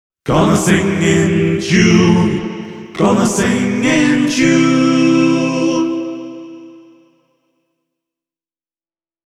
Kitarasyöttöä hyödyntävä Auto Harmonist -toiminta antaa käyttäjälle paljon enemmän valinnanvaraa, ja lopputuloksesta tulee vielä entistäkin luonnollisemman kuuloista. Tässä kaksi eri Auto Harmonistilla laulettua esimerkkiä: